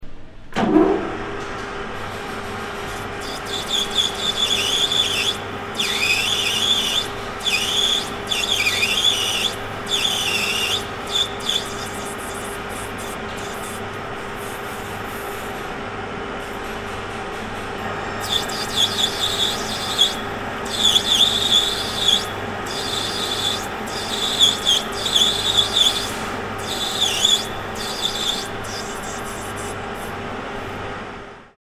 The turner taps
The turner is working on an object to tap it by 9 mm.